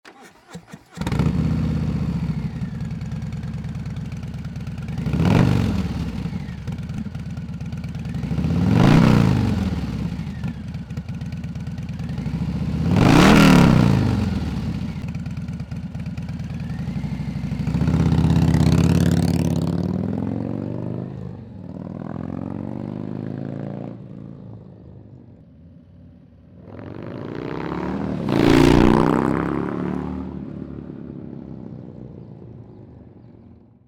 Extract a deep and aggressive roar out of your M8 Softail with a factory 2-1 header when you strap on our bold new Supersport Slip-on.